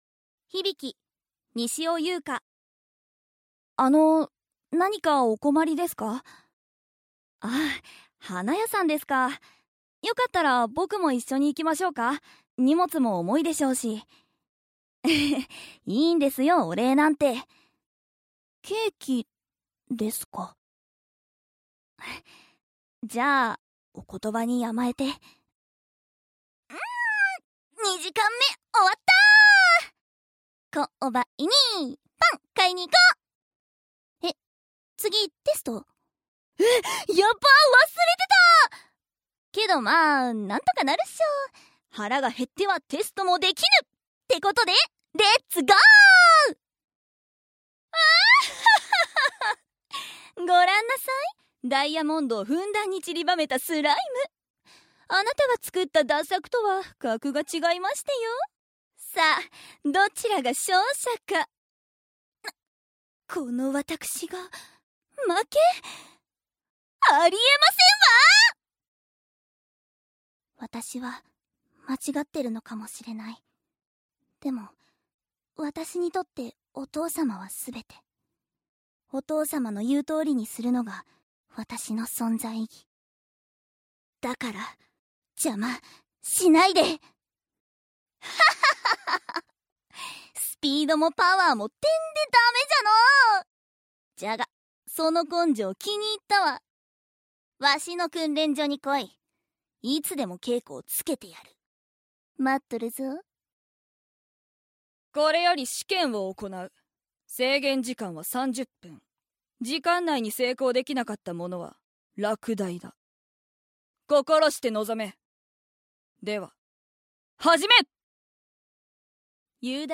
【響】西尾夕香ボイスサンプル